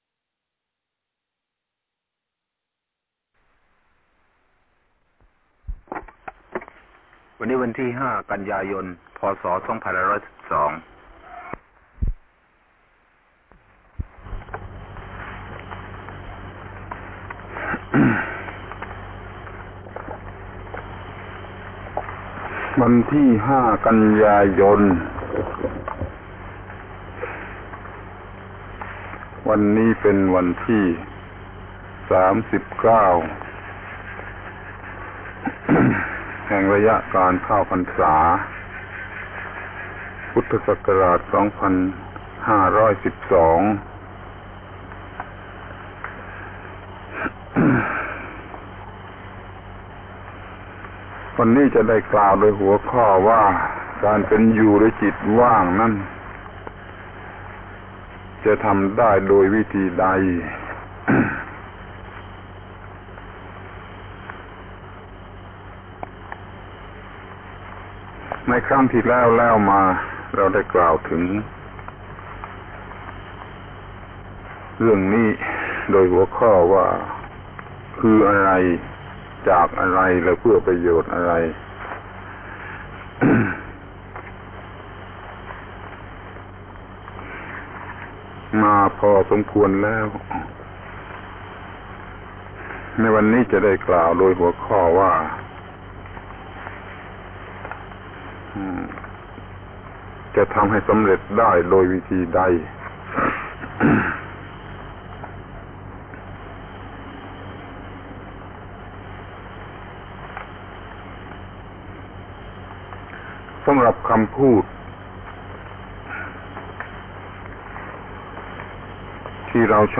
พระธรรมโกศาจารย์ (พุทธทาสภิกขุ) - อบรมพระนวกะในพรรษา ปี 2512 ครั้ง 24 การเป็นอยู่ด้วยจิตว่างจะสำเร็จประโยชน์โดยวิธีใด